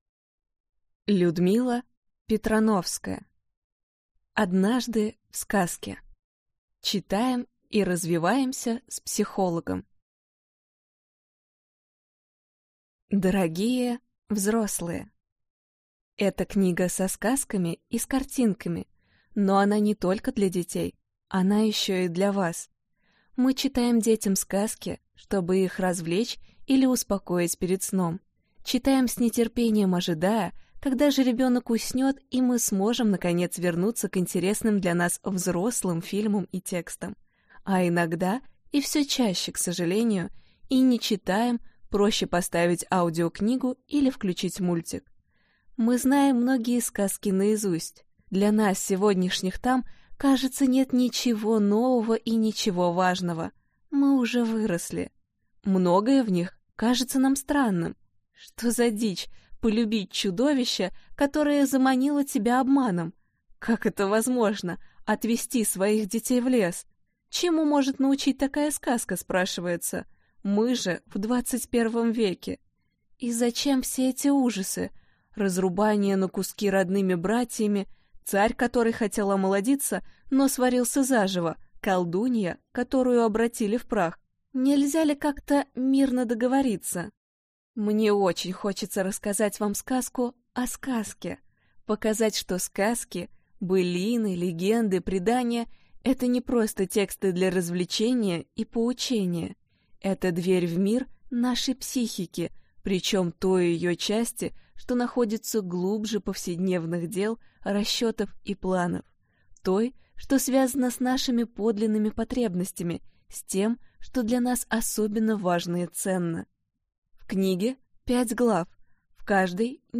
Аудиокнига Однажды в сказке. Читаем и развиваемся с психологом | Библиотека аудиокниг